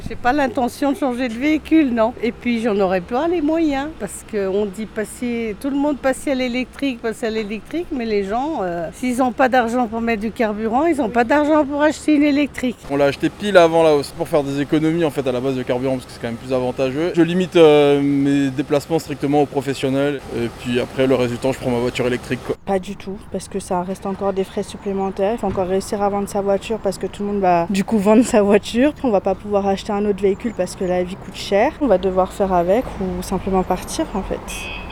Nous vous avons posé la question, devant la pompe, ces derniers jours en Haute-Savoie.